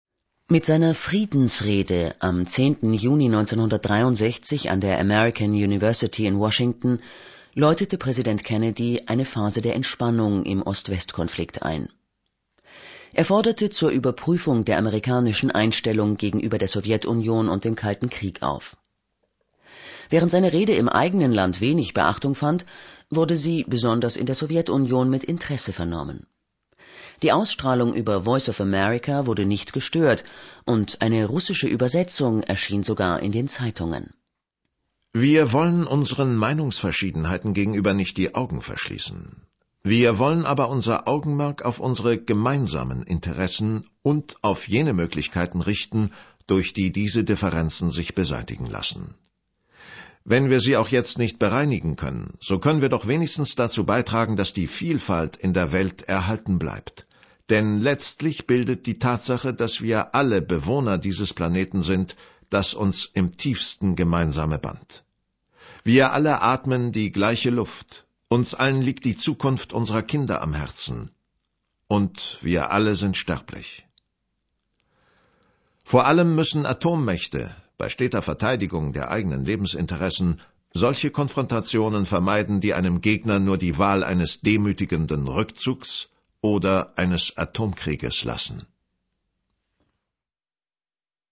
Mit seiner „Friedensrede“ am 10. Juni 1963 an der American University in Washington läutete Präsident Kennedy eine Phase der Entspannung im Ost-West-Konflikt ein. Er forderte zur Überprüfung der amerikanischen Einstellung gegenüber der Sowjetunion und dem Kalten Krieg auf.